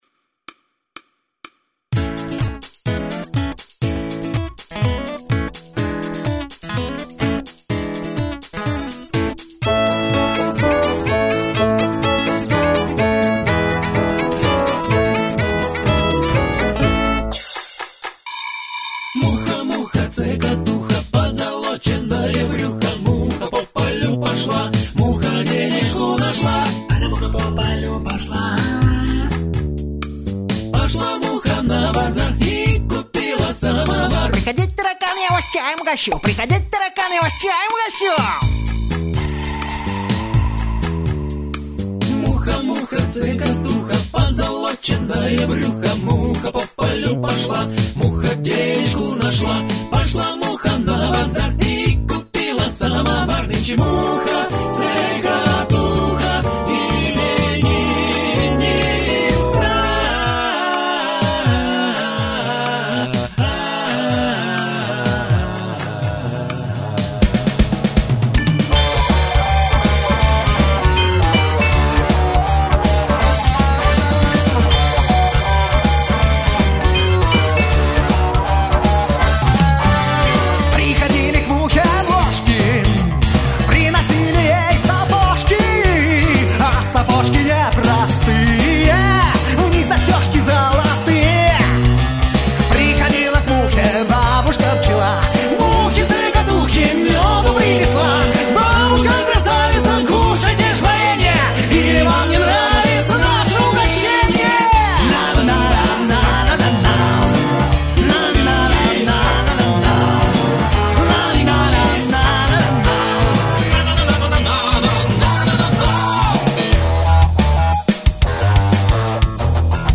Нормально поют дядьки)))
гитара, клавиши
основной вокал
практически весь бэк-вокал